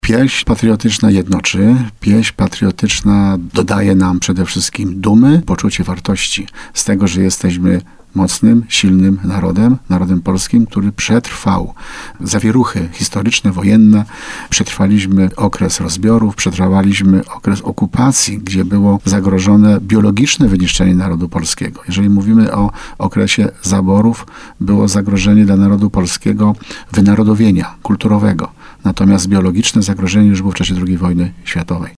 mówi wójt gminy Tarnów i historyk Grzegorz Kozioł.